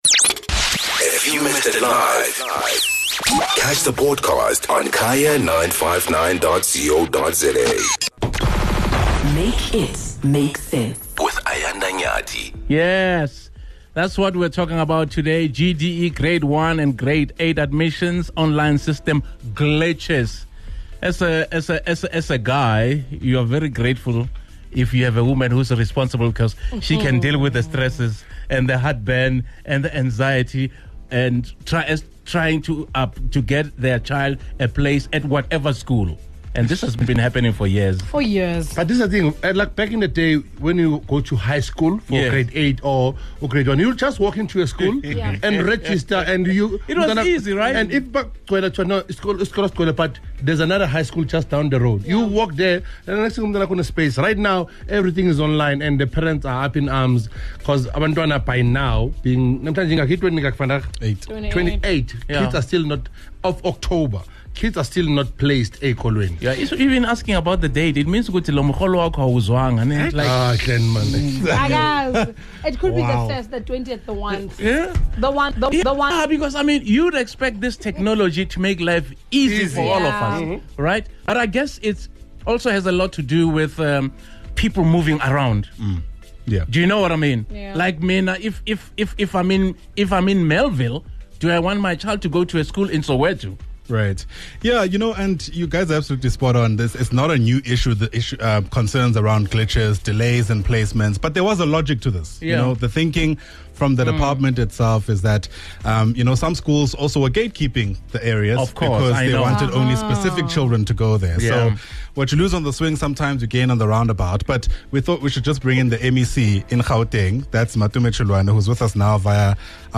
Gauteng Education MEC Matome Chiloane joins us to helped parents make sense of this complicated matter.